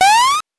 powerup_21.wav